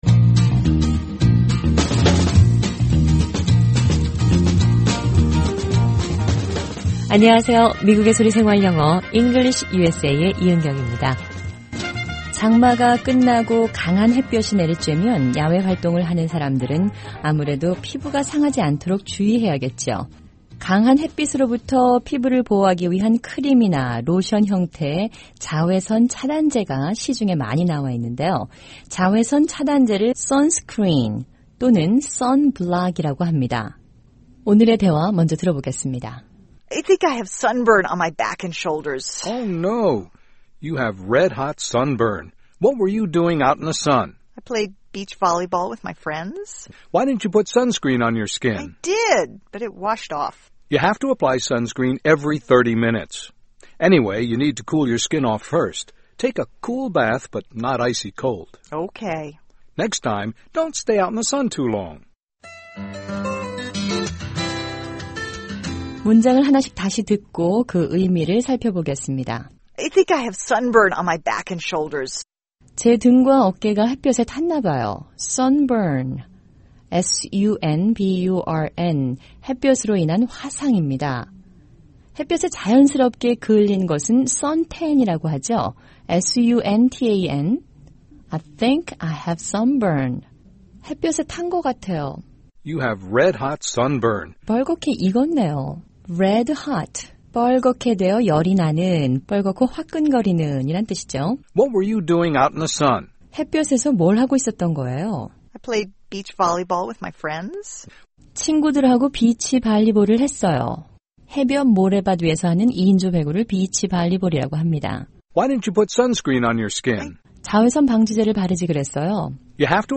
일상생활에서 자주 사용하는 영어를 배우는 시간 'VOA 현장영어' 입니다. 오늘은 강한 햇볕에 노출된 피부를 보호하는 방법에 대한 대화를 들어봅니다.